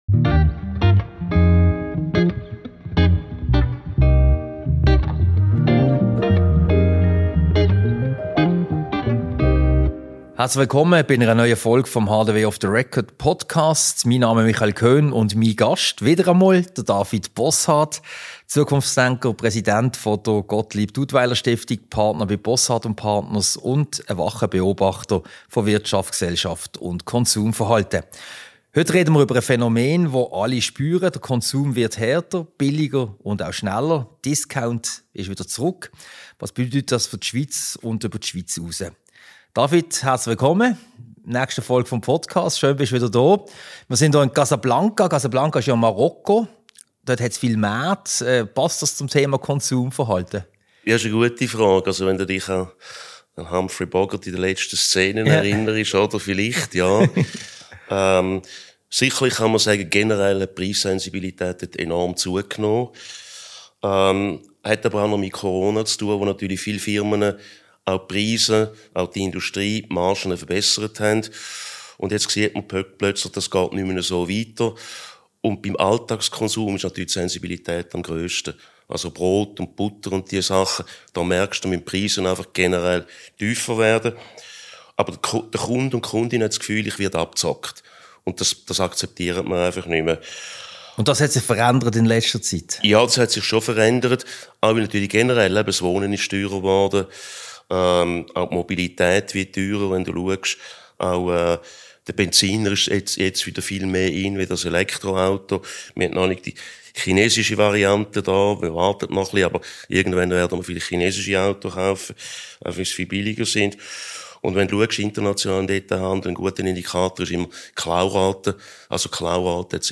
Ein Gespräch über verschiedene Aspekte des Konsums sowie des Konsumverhaltens und was das für die Schweiz bedeutet. Diese Podcast-Ausgabe wurde als Video-Podcast im Sitzungszimmer Casablanca im Haus der Wirtschaft HDW aufgezeichnet.